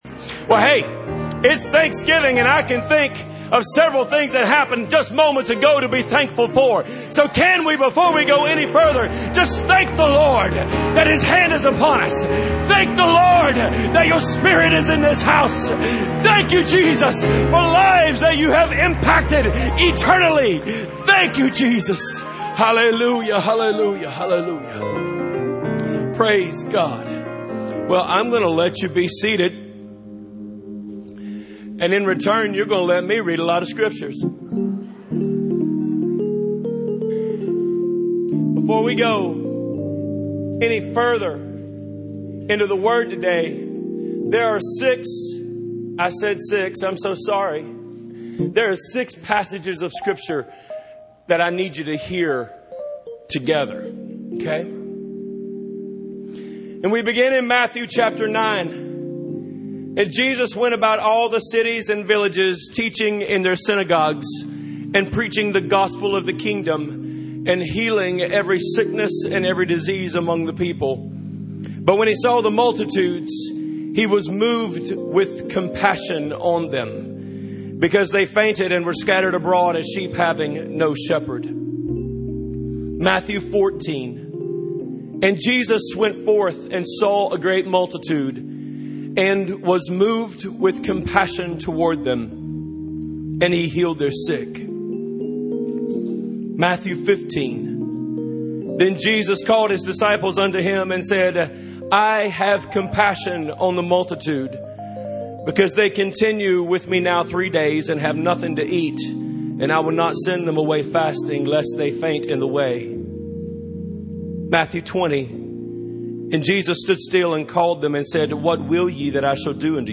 The Calvary Apostolic Church Sermon Archive